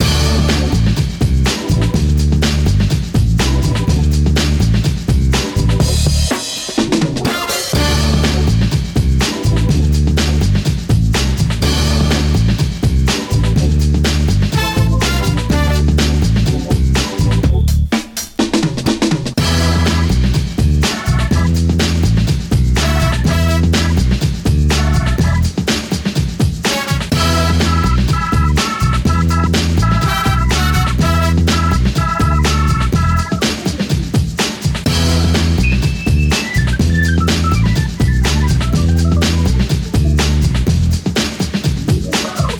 melodía
repetitivo
rítmico
sintetizador
soul
Sonidos: Música